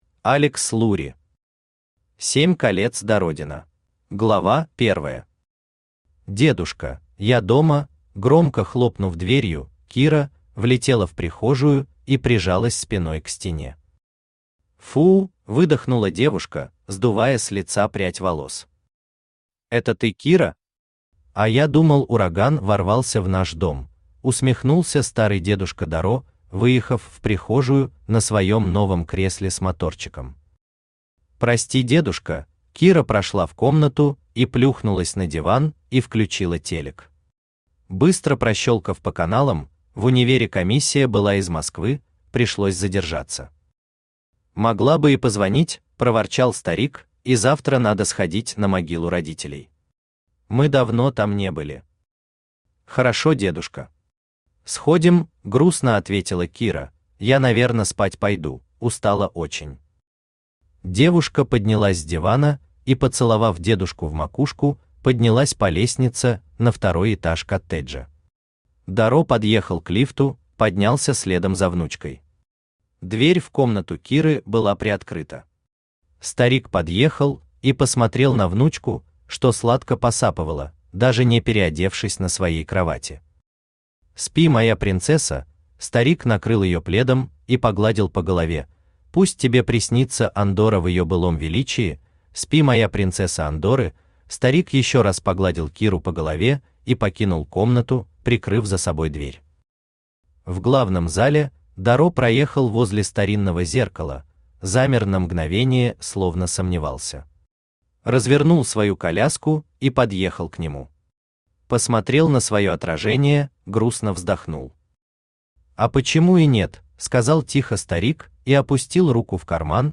Аудиокнига Семь колец Дародена | Библиотека аудиокниг
Aудиокнига Семь колец Дародена Автор Алекс Лури Читает аудиокнигу Авточтец ЛитРес.